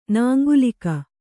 ♪ nāŋgulika